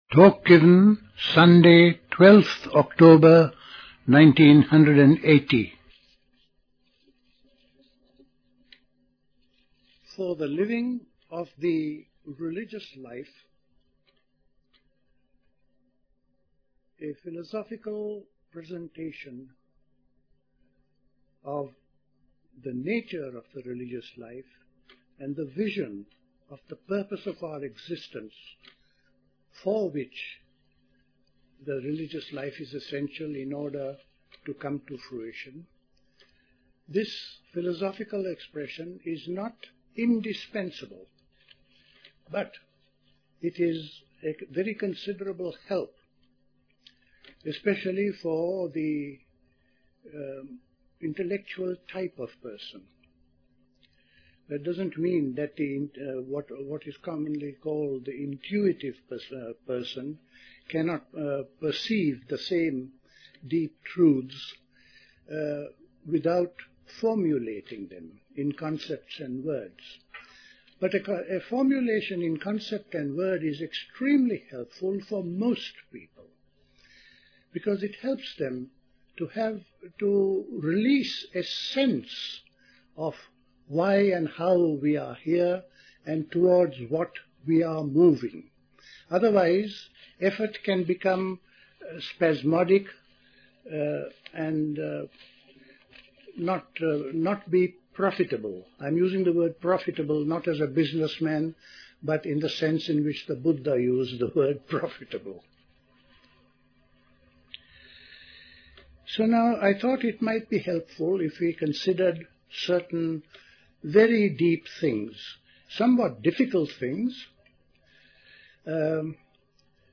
Play Talk